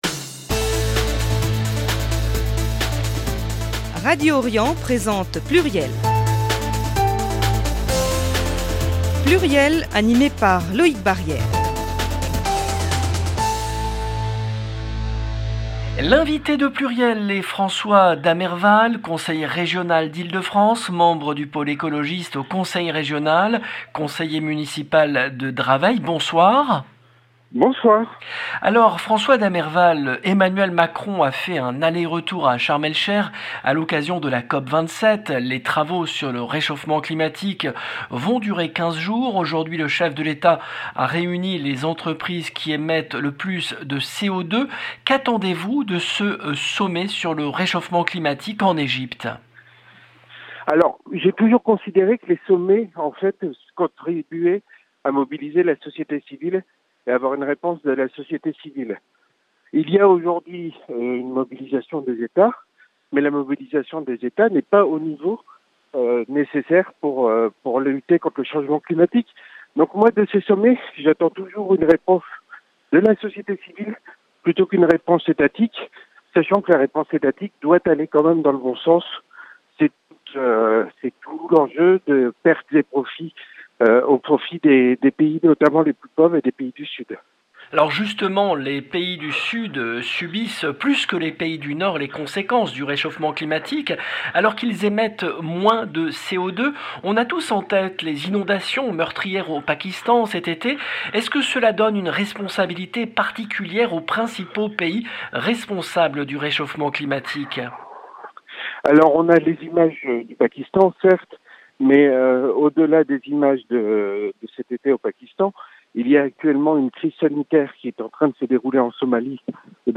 L’invité de PLURIEL est François Damerval , conseiller régional d’Ile-de-France, membre du Pôle écologiste au Conseil régional, conseiller municipal de Draveil